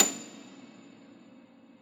53e-pno29-A6.wav